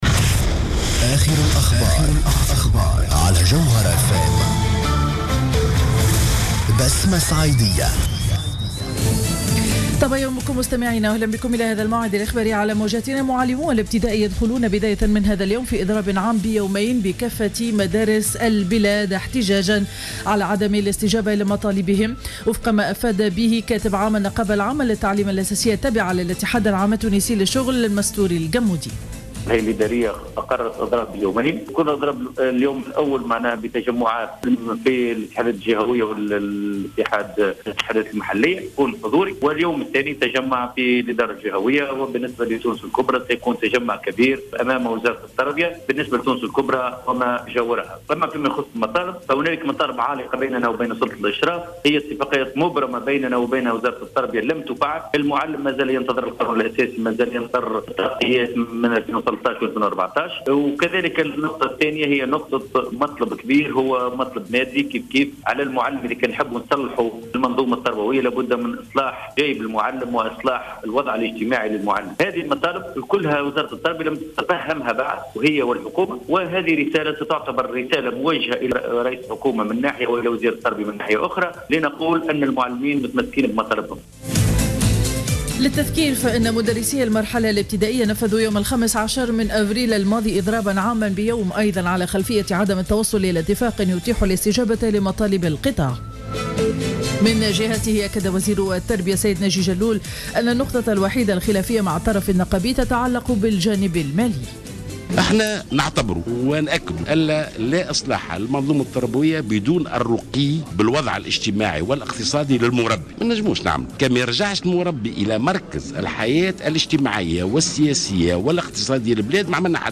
نشرة أخبار السابعة صباحا ليوم الثلاثاء 12 ماي 2015